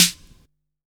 Index of /90_sSampleCDs/USB Soundscan vol.20 - Fresh Disco House I [AKAI] 1CD/Partition C/09-SNARES